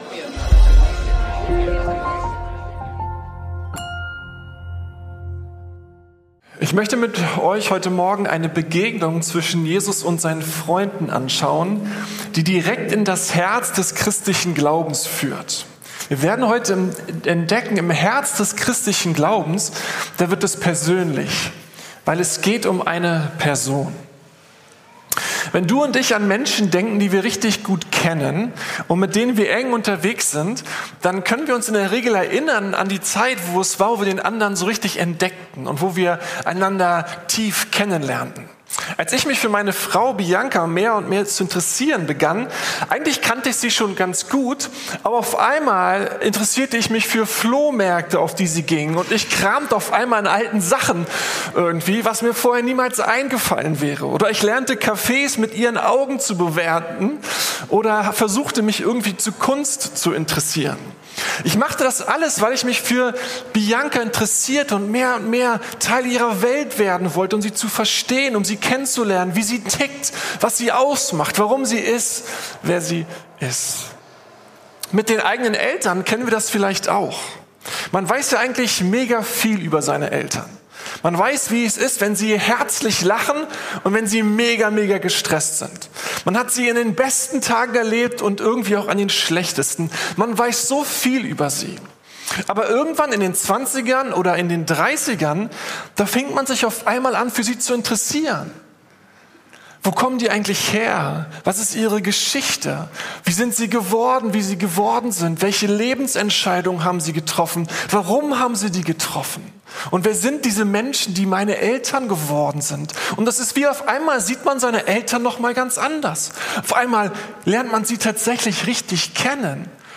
Einem persönlichen Gott folgen ~ Predigten der LUKAS GEMEINDE Podcast